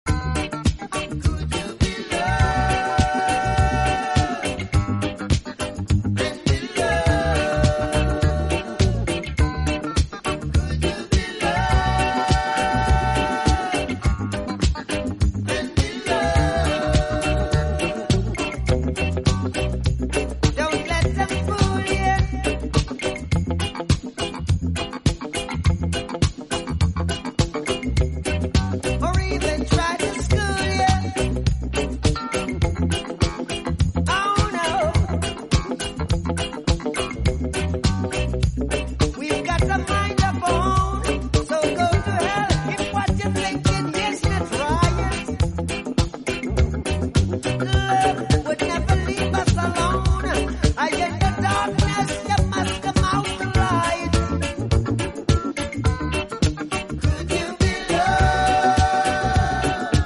blending reggae, funk, and disco into a groove